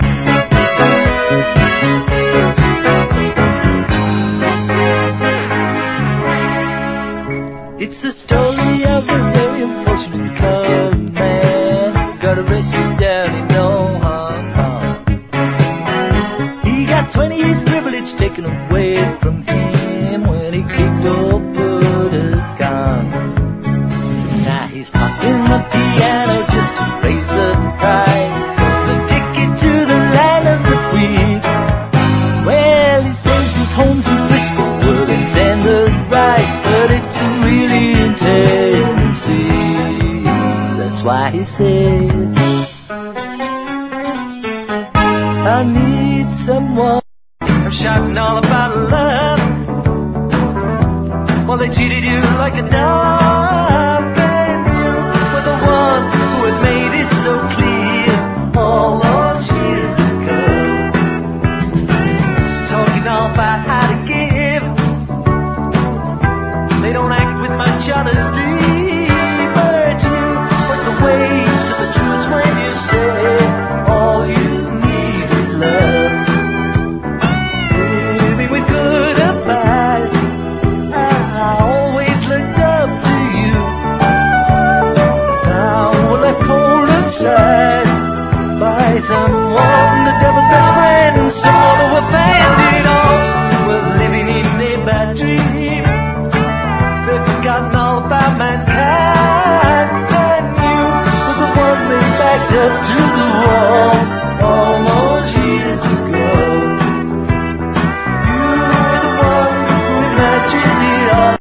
ROCK / 70'S
サイケデリックで幻想的に美しい